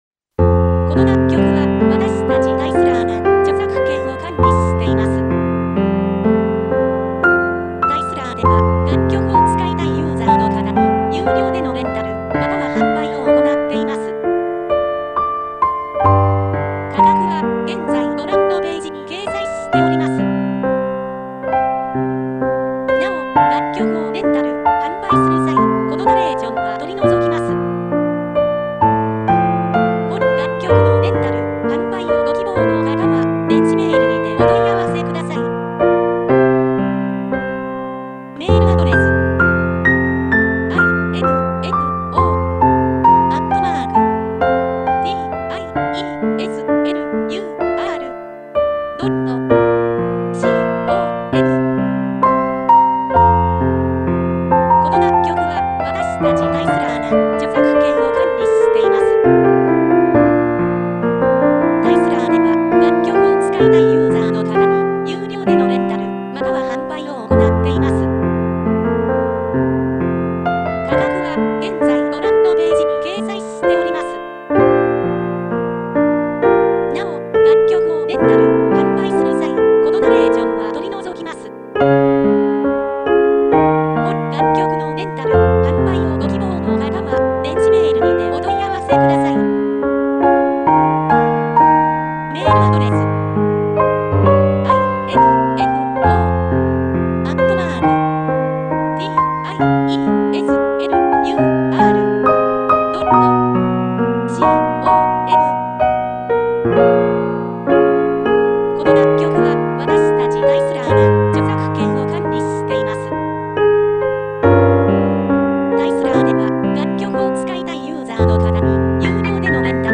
■ピアノ曲のレンタル・販売ページ
●メジャーキー・スローテンポ系